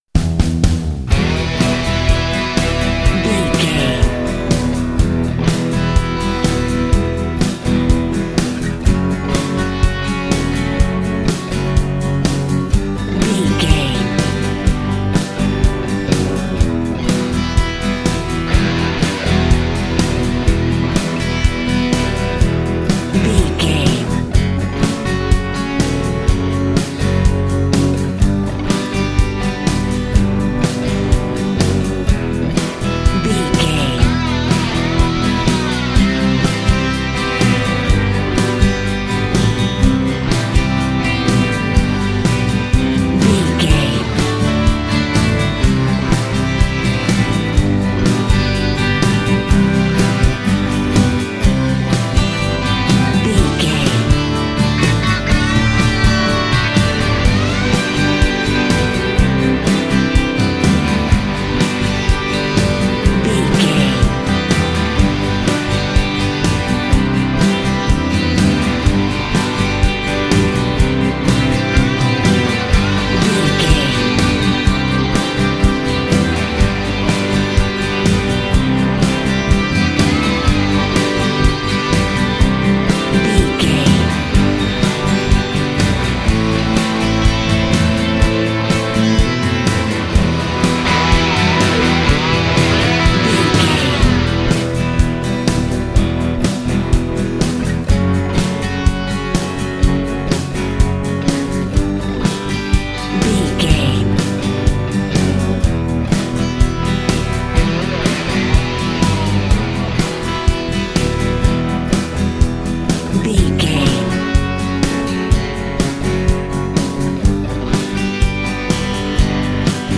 Aeolian/Minor
electric guitar
bass guitar
drums
hard rock
lead guitar
aggressive
energetic
intense
powerful
nu metal
alternative metal